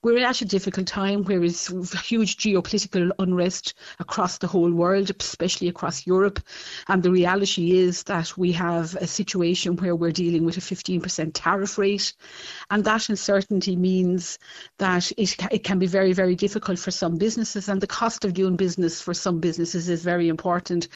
Mental Health Junior Minister Mary Butler says steps will be taken to help firms deal with President Donald Trump’s tariffs: